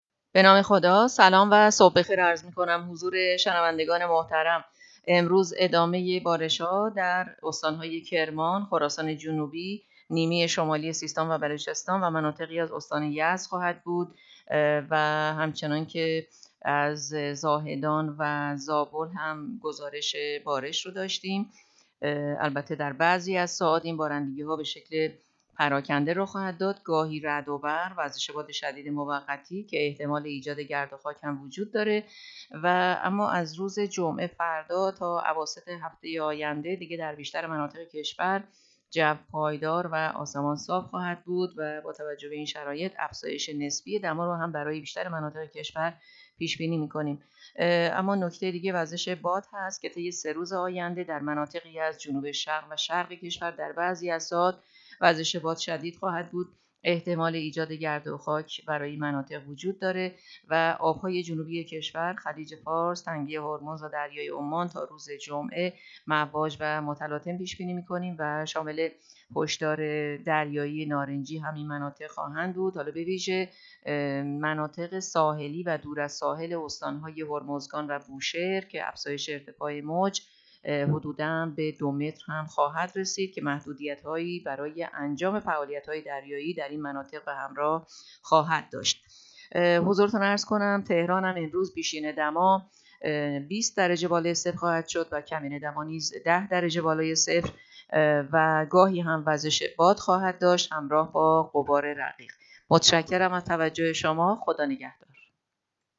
گزارش رادیو اینترنتی پایگاه‌ خبری از آخرین وضعیت آب‌وهوای ۲۳ اسفند؛